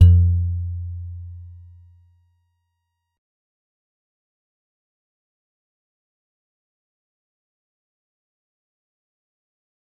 G_Musicbox-F2-mf.wav